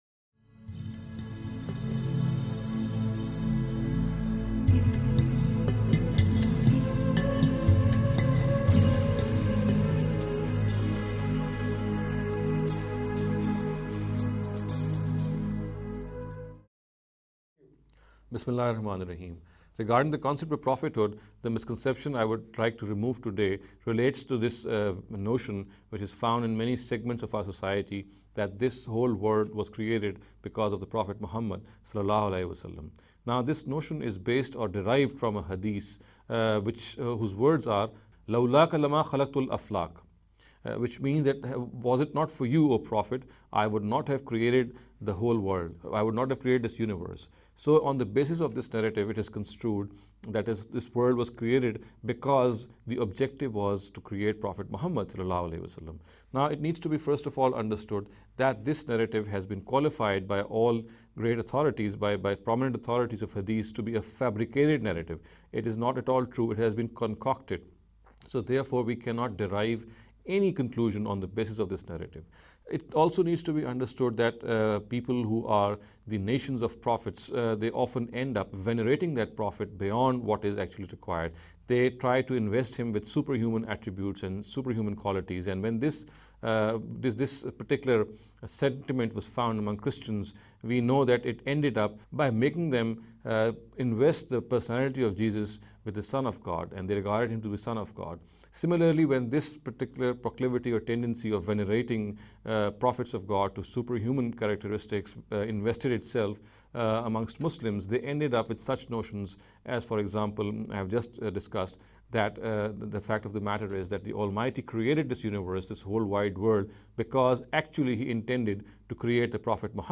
This lecture series will deal with some misconception regarding the Concept of Prophethood.